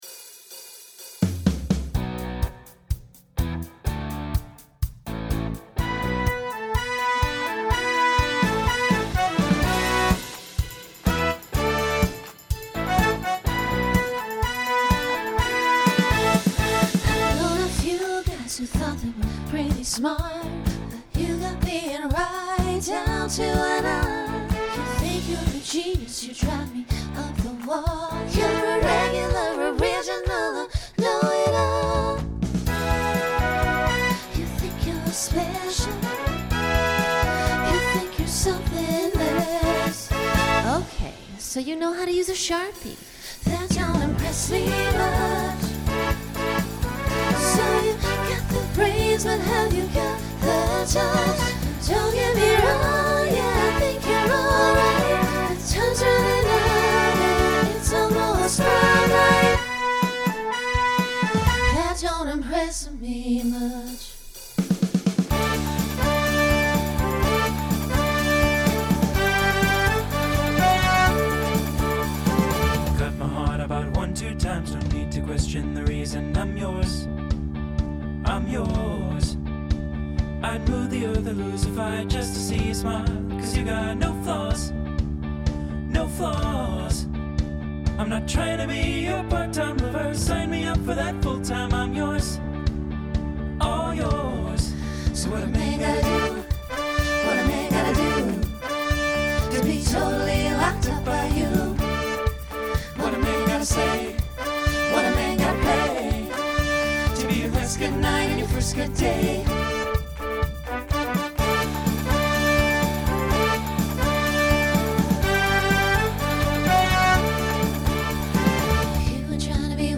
Genre Country , Rock
Voicing SATB